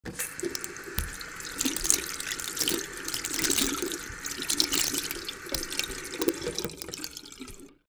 Washing Hands
Washing Hands.wav